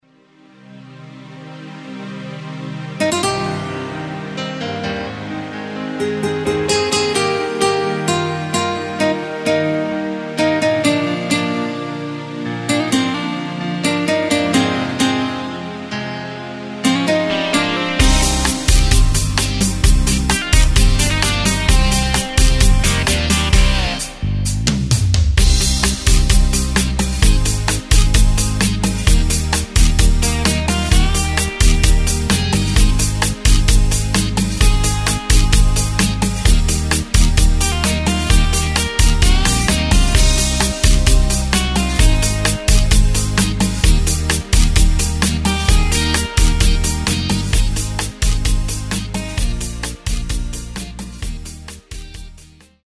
Takt:          4/4
Tempo:         130.00
Tonart:            Eb
Rumänischer Song aus dem Jahr 2010!
Playback mp3 Demo